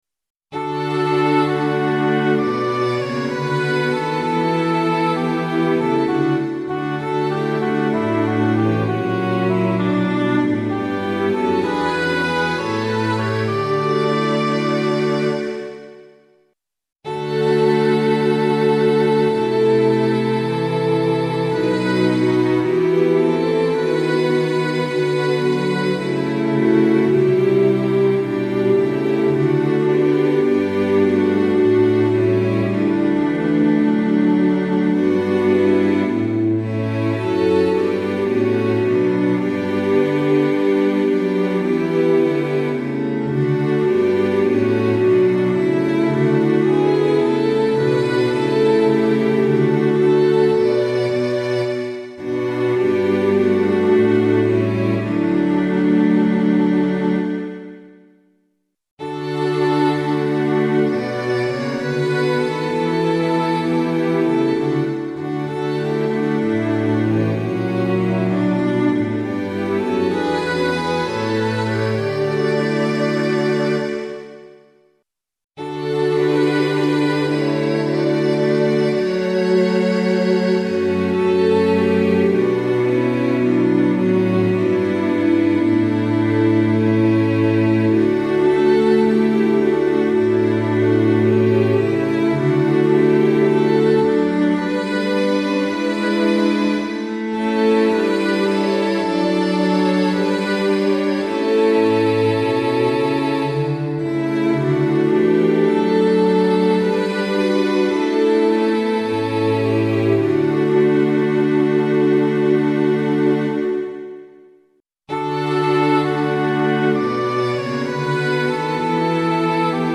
混声四部合唱+器楽 Four-part mixed chorus with Instruments
Sample Sound ：参考音源 - 様々な音色の組み合わせによる
DL Ob+Str Rcd+Str Fl+Str Rcd+Str Ob+Str